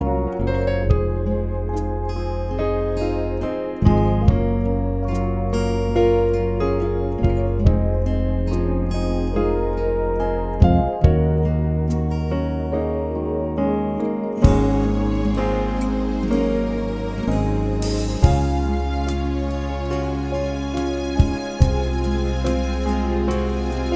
No Electric Guitar Pop (1980s) 3:13 Buy £1.50